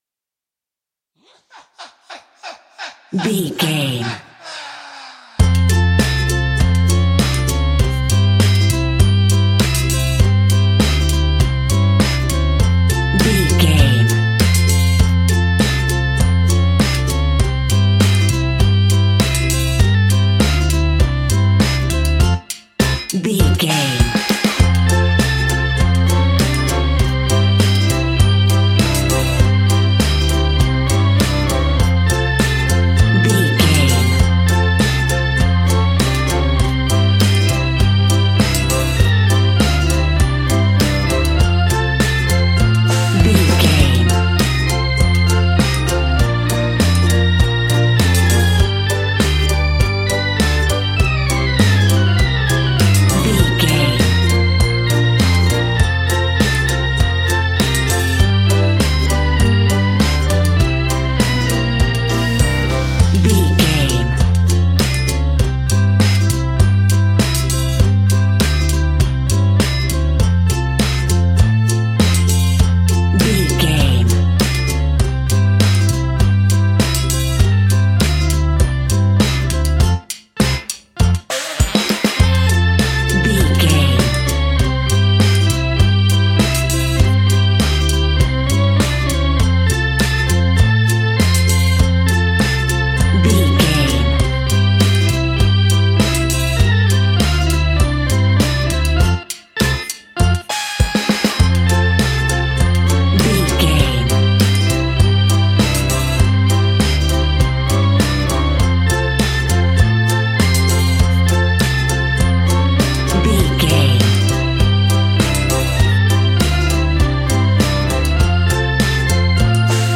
In-crescendo
Aeolian/Minor
ominous
suspense
eerie
horror music
Horror Pads
horror piano
Horror Synths